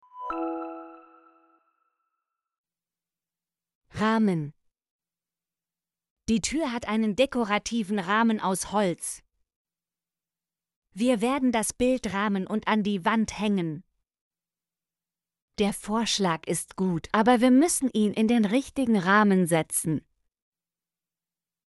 rahmen - Example Sentences & Pronunciation, German Frequency List